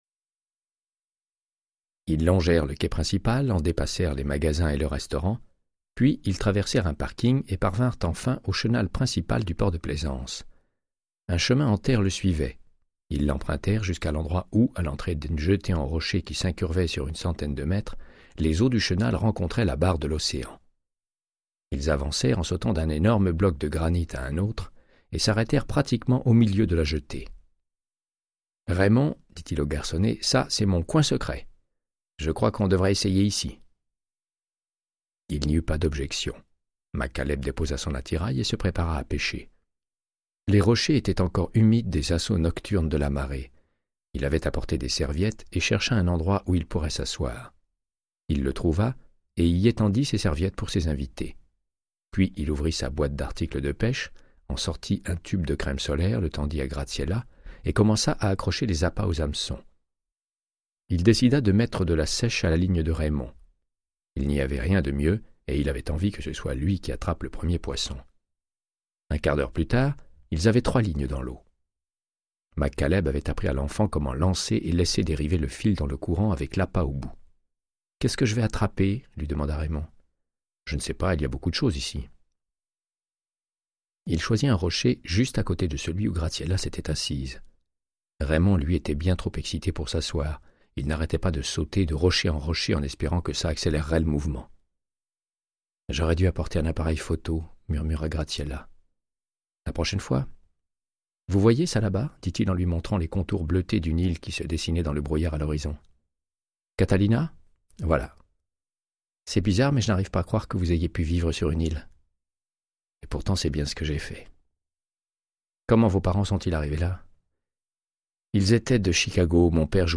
Audiobook = Créance de sang, de Michael Connellly - 88